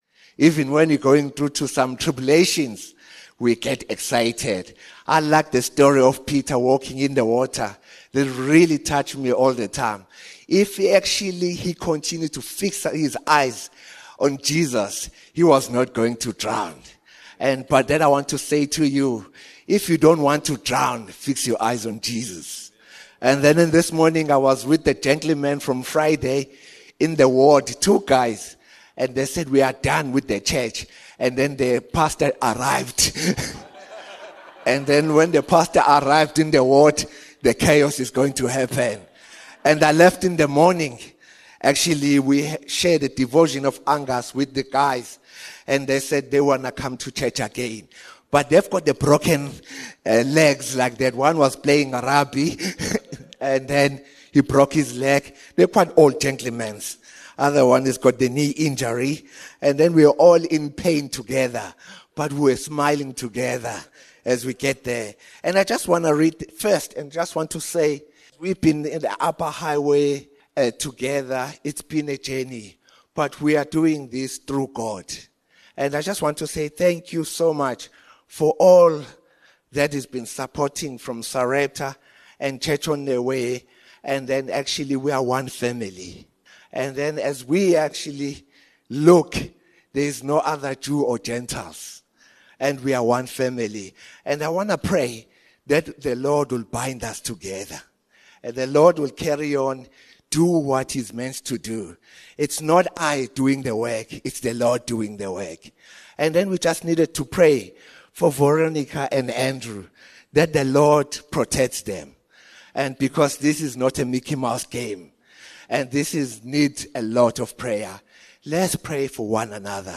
Sunday message 24 November